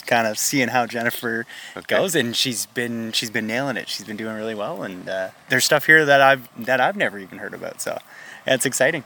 Even long-time residents learned something new about their town as one participant explained.